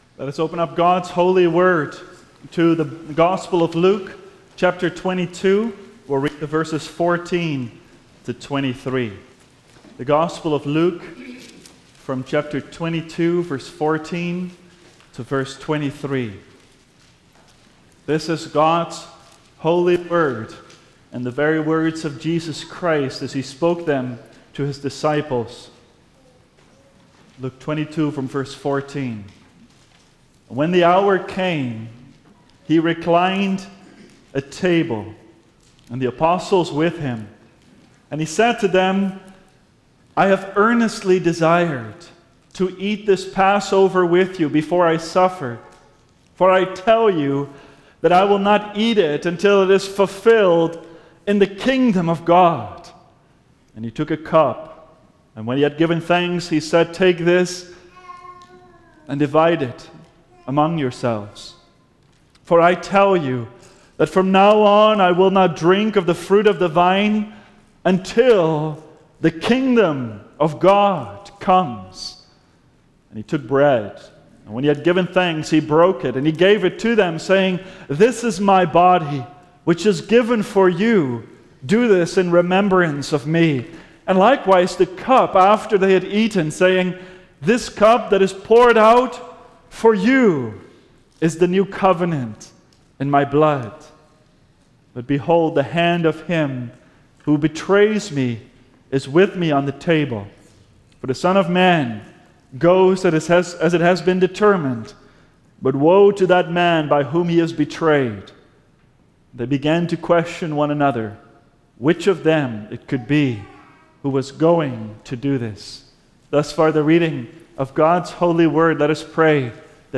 Passion and Easter Sermons